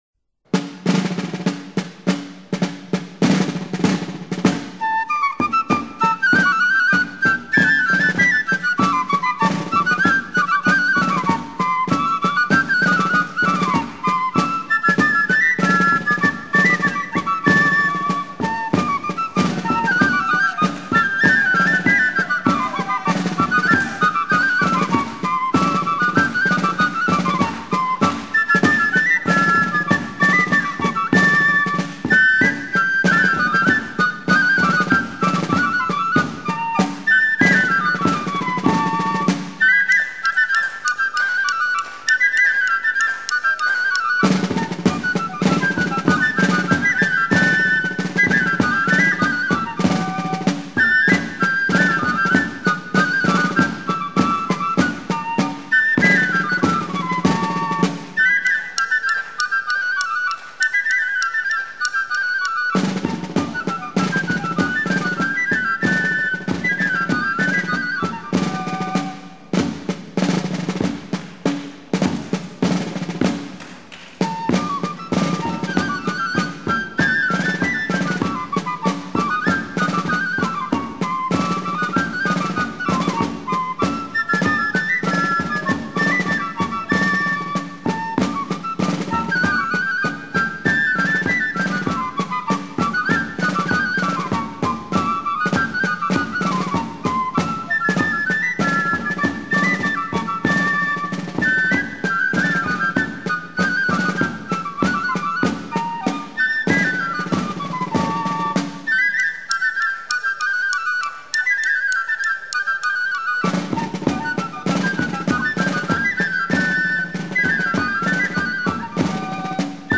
Marche
marche de cortège et de procession lors de fêtes de villages de la côte belge
Pièce musicale éditée